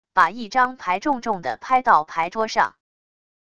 把一张牌重重地拍到牌桌上wav音频